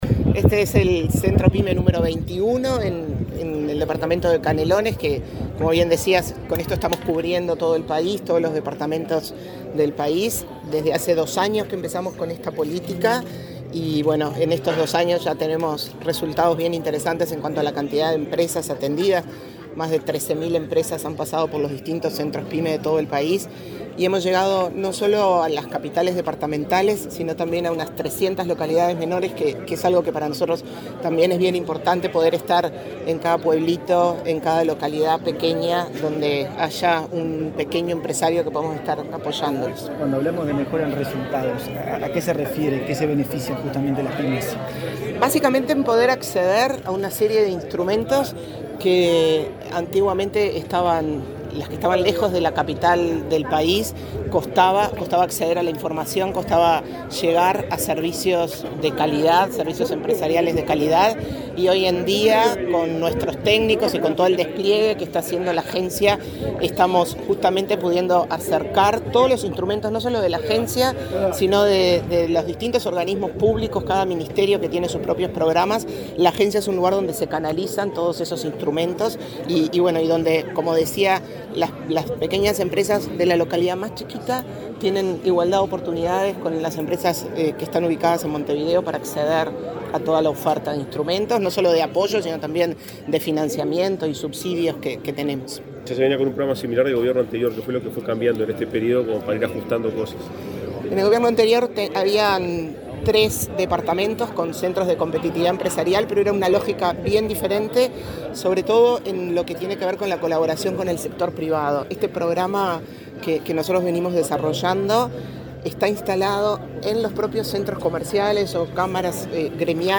Declaraciones de la presidenta de ANDE, Carmen Sánchez
Antes, dialogó con la prensa.